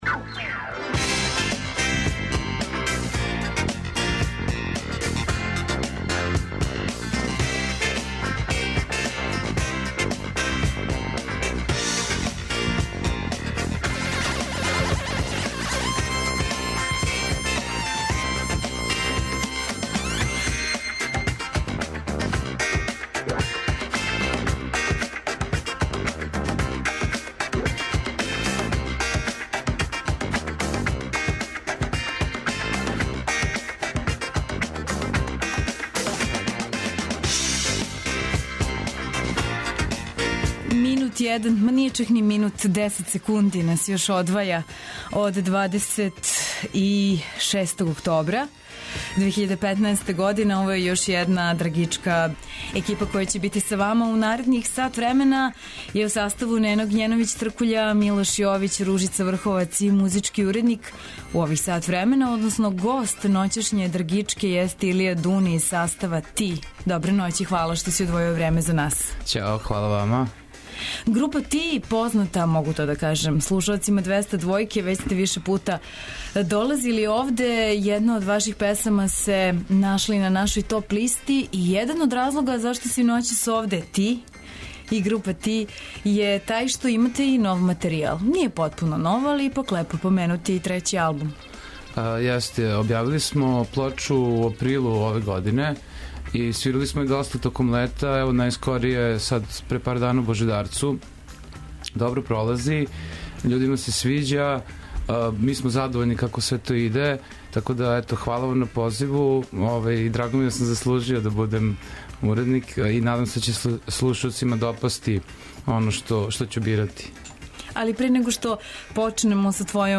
У 'Драгичку' ноћас долази група 'Ти'.